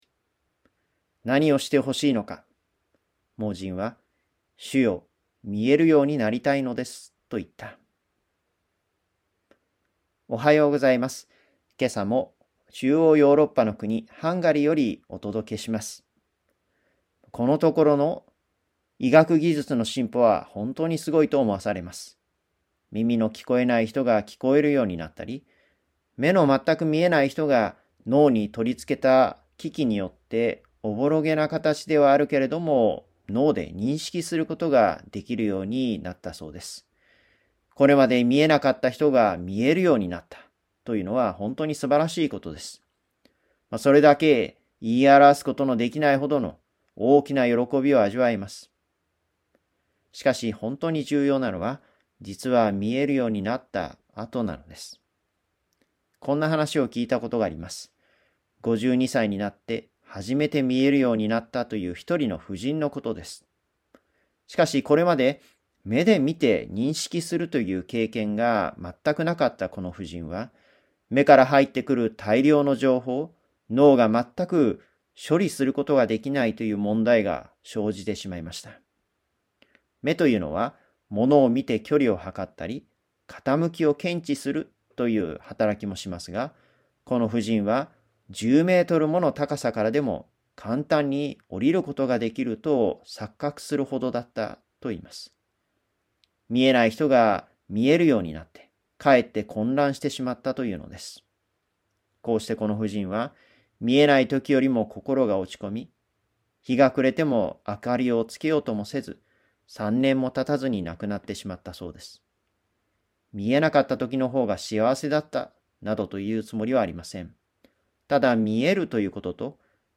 ラジオ番組「キリストへの時間」
今朝も、中央ヨーロッパの国ハンガリーよりお届けします。